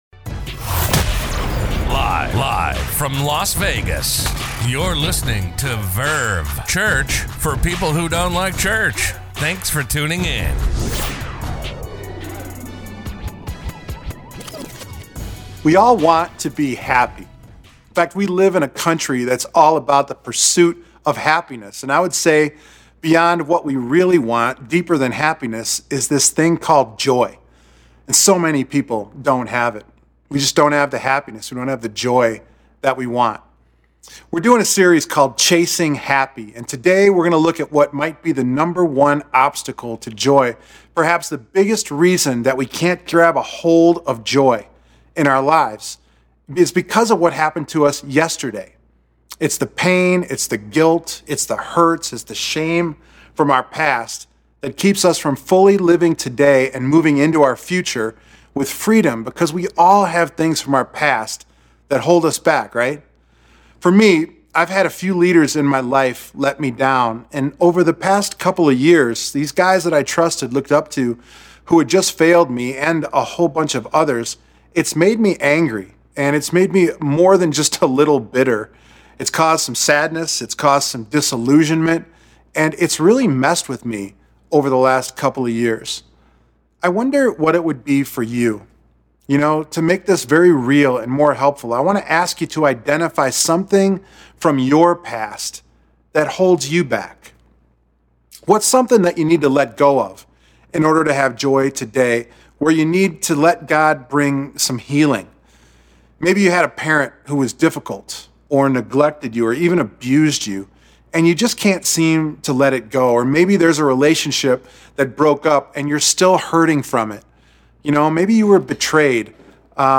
A message from the series "Hot Ones!." Temptation is everywhere and temptation is ... tempting! So, how do we say no to temptation and avoid train wrecking our lives?